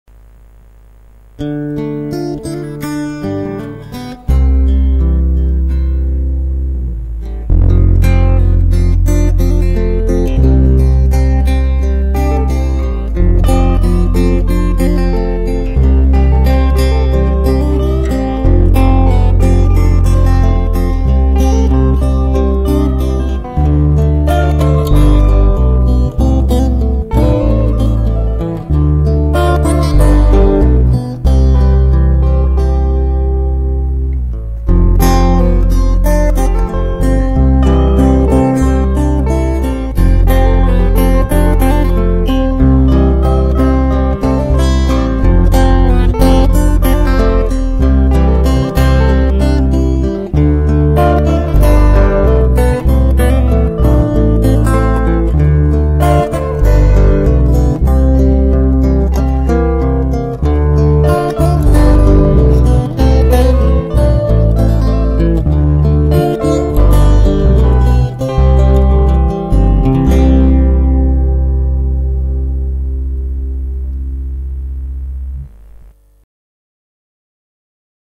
Bajo eléctrico, guitarras acústica y eléctrica.